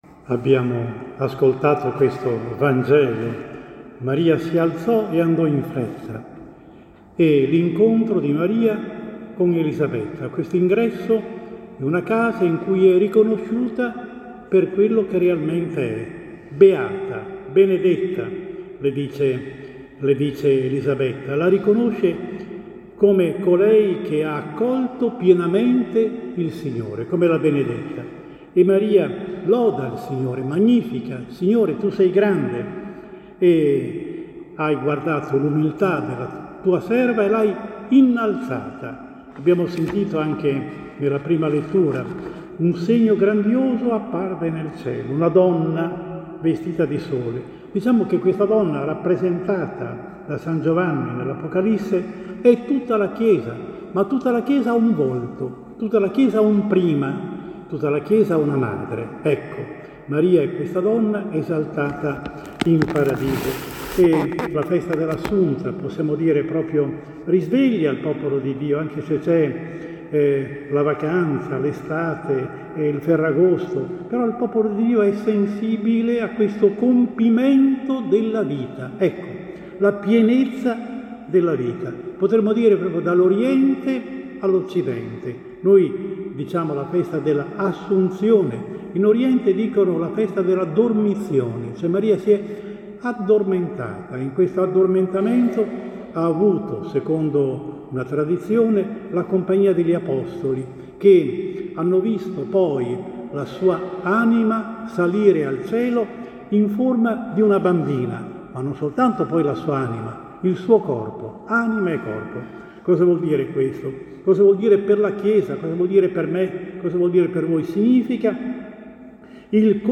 Lunedi 15 Agosto 2022 – ASSUNZIONE DELLA BEATA VERGINE MARIA: omelia